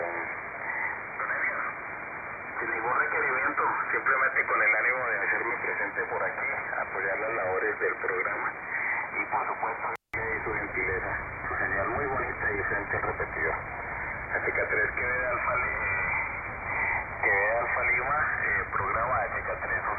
Audio comparisons between Airspy HF+ and Winradio G33DDC Excalibur Pro
No noise reduction (NR) was used.
Second 0-10 >> Winradio G33DDC Excalibur Pro
Second 10-20 >> Airspy HF+
Amateurfunk
7093KHz-LSB-Amateurfunk.mp3